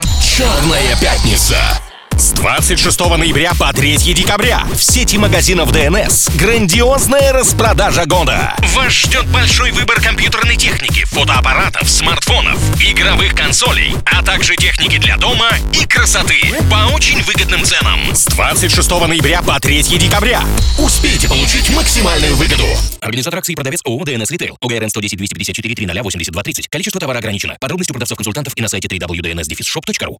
Стартовало размещение рекламы на радиостанции "Радио Дача" компании "ДНС" в г. Кирове.